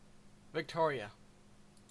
Uttal
Uttal CA Okänd accent: IPA : /viktɔɹiːə/ Förkortningar (lag) Vict.